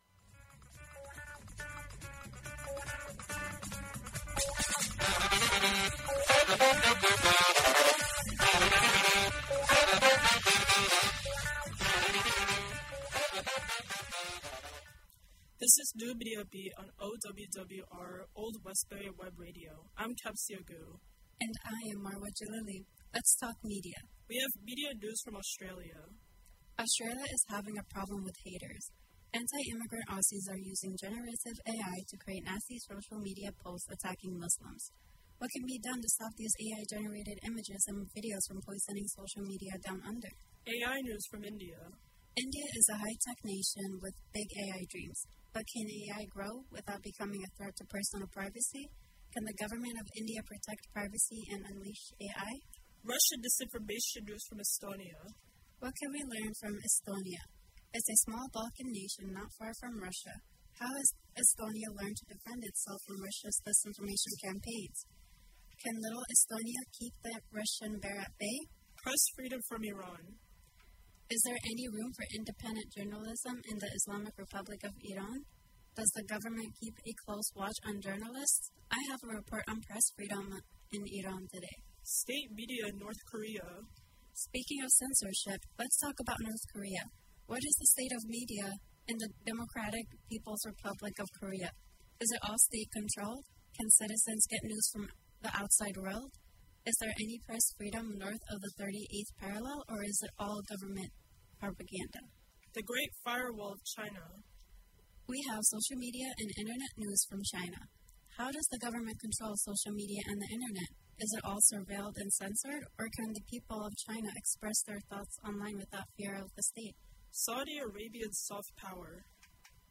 We are streaming live again on Old Westbury Web Radio Thursdays from 10:15-11:15 AM EST. Can’t listen live?